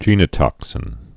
(jēnə-tŏksĭn)